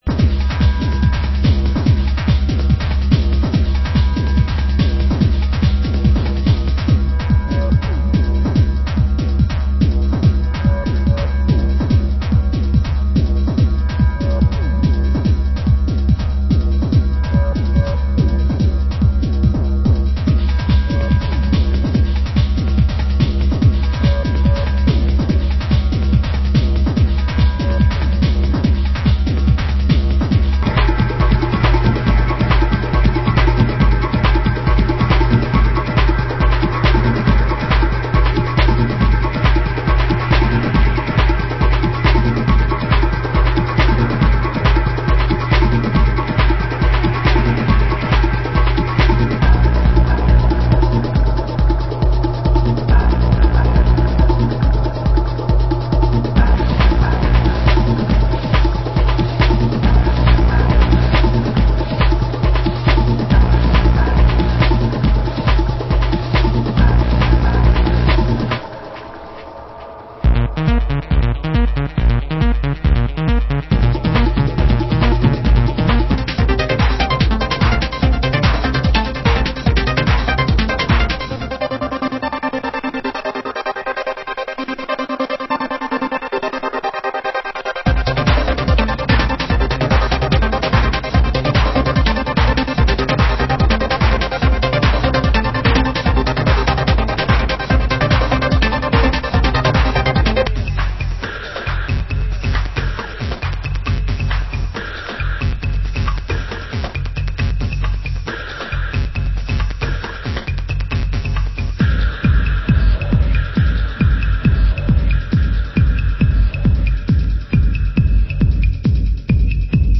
Genre: Euro Techno